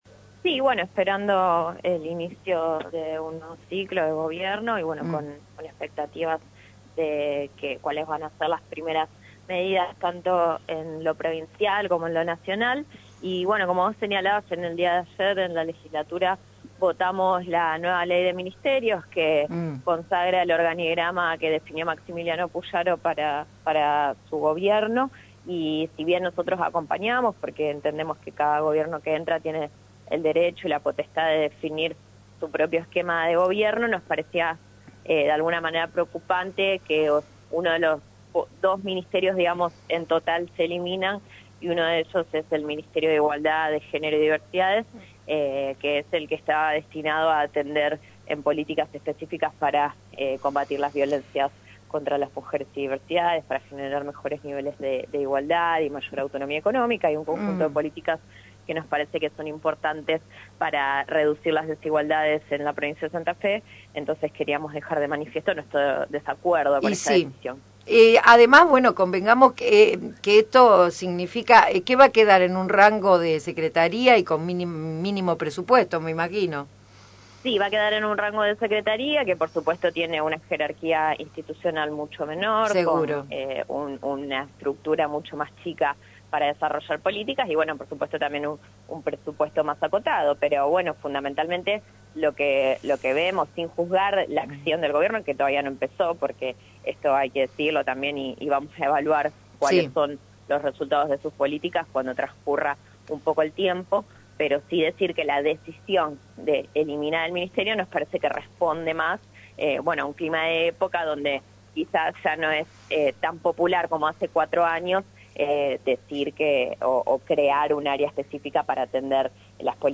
Hoy día cuando ya es una concreción en el armado del gabinete santafesino, «Un día de Gloria» se comunicó con la diputada Lucila De Ponti, reconocida militante por los derechos de la mujer y las diversidades que expresa su opinión sobre las posibles consecuencias de esta medida.